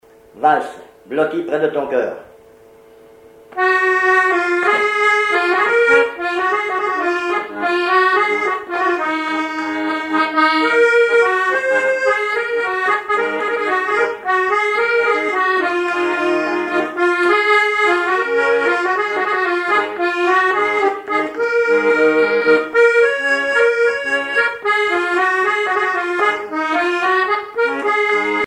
accordéon(s), accordéoniste
Pièce musicale inédite